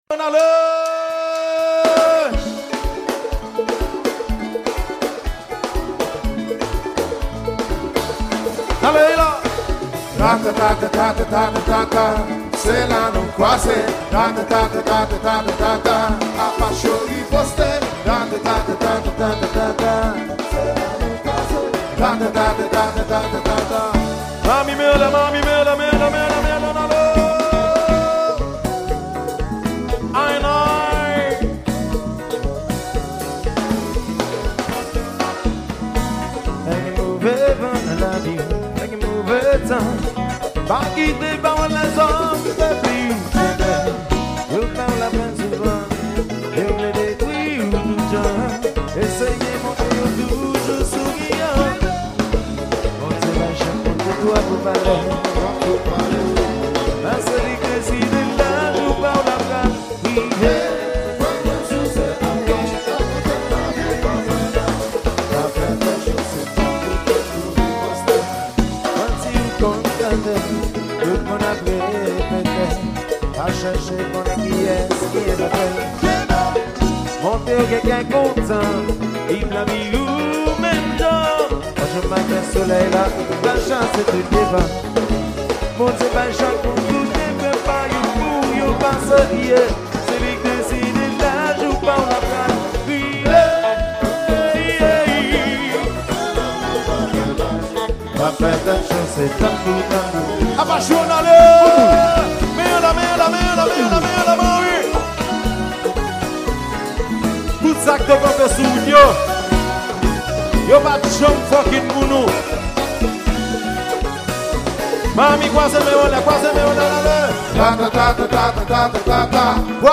Genre: KONPA LIVE.